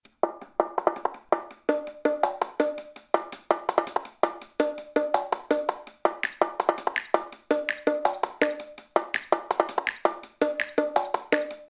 "ambiance 1.wav"
son d'ambiance